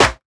Snare (42).wav